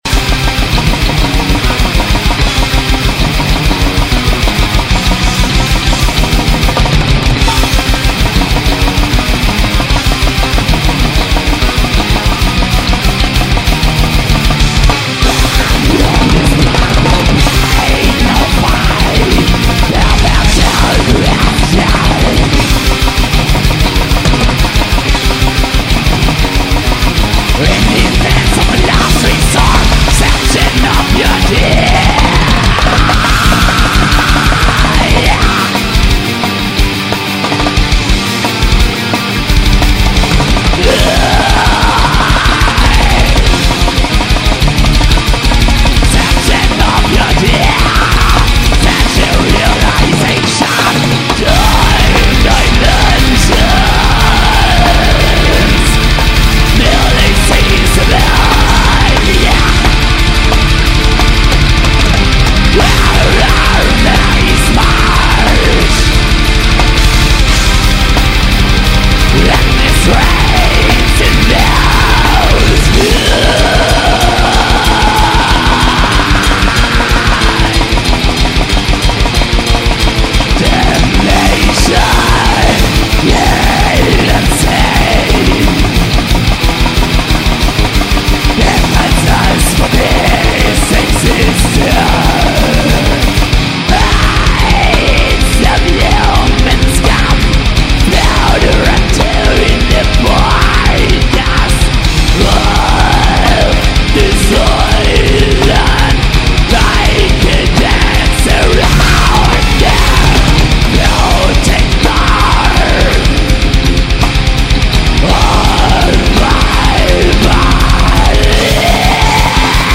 блэк-металлеры